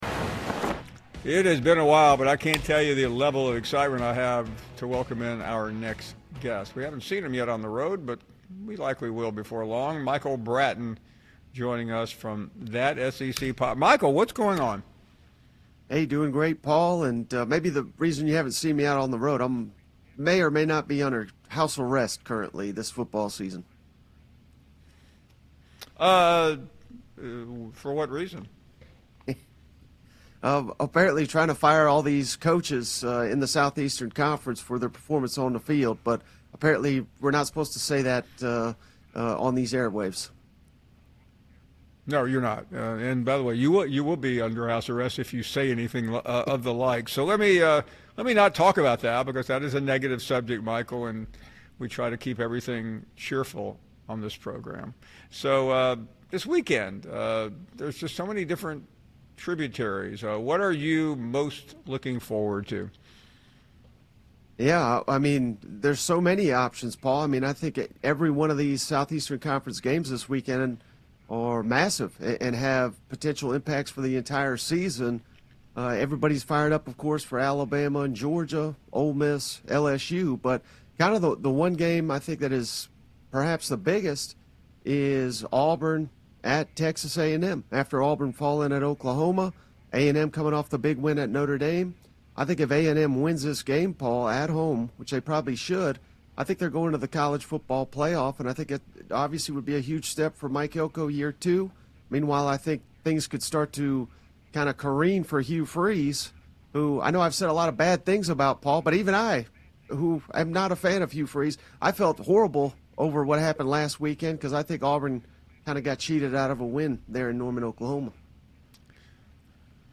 Hear their Sports Talk about Georgia Football and Alabama Football.